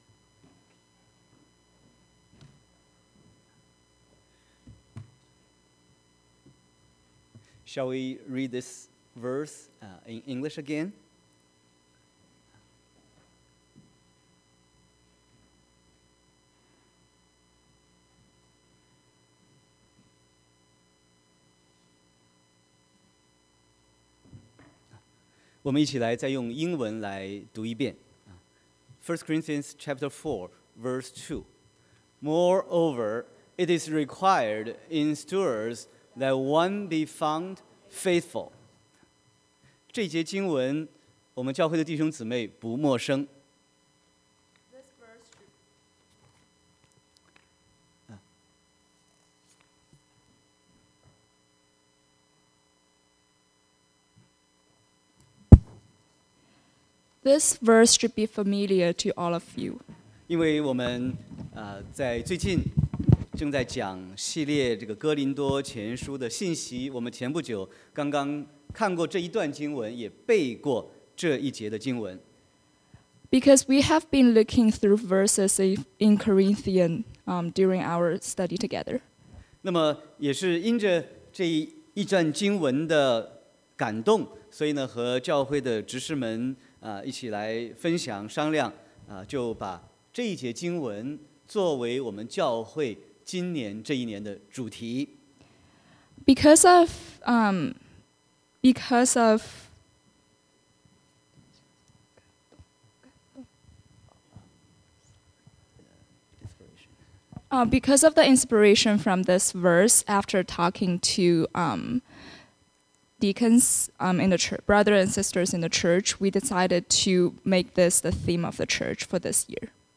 Sermon 01/06/2019